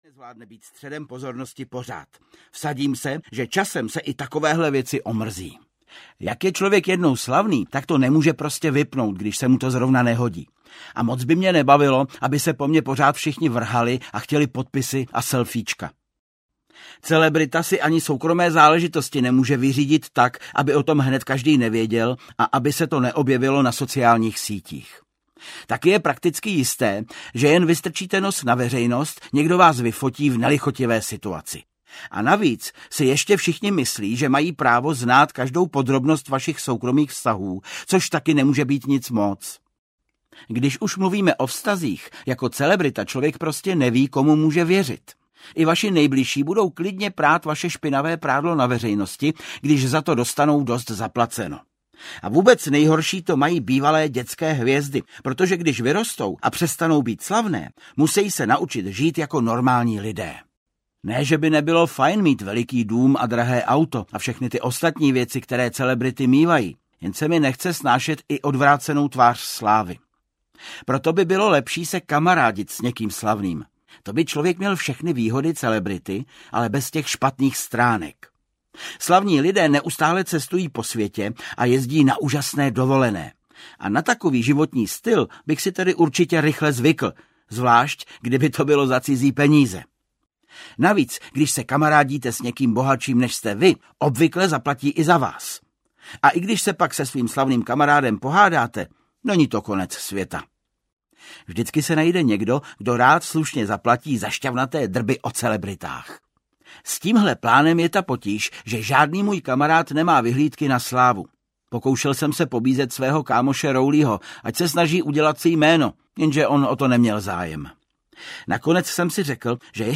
Audio knihaDeník malého poseroutky 17
Ukázka z knihy
• InterpretVáclav Kopta